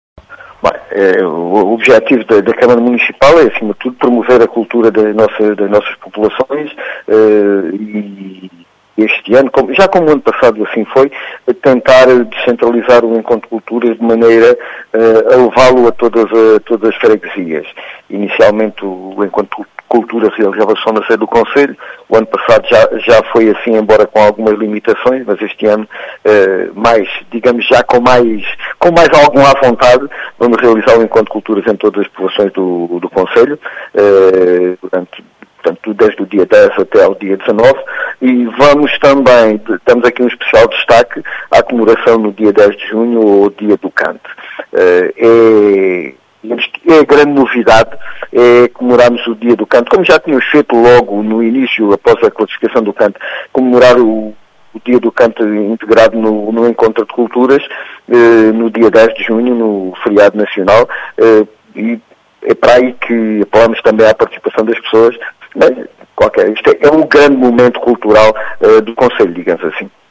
As explicações são de João Efigénio Palma, presidente da Câmara Municipal de Serpa, que fala no “grande momento cultural” do concelho.